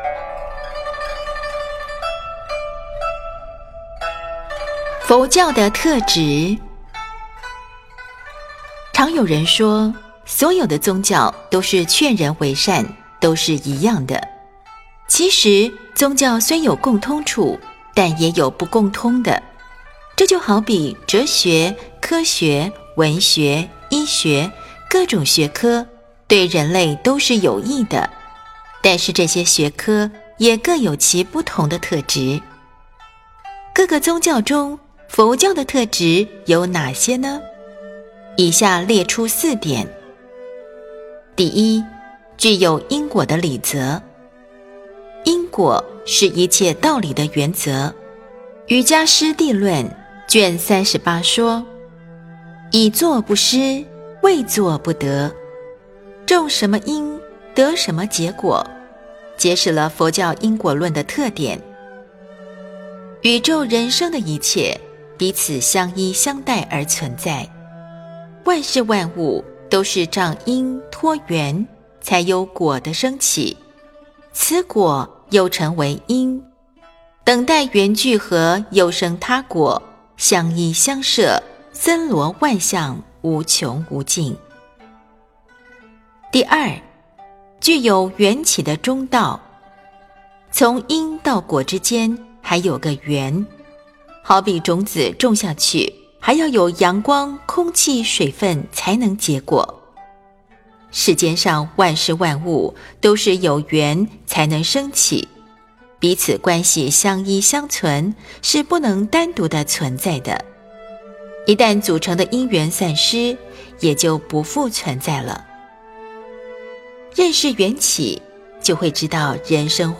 佛教的特质 诵经 02.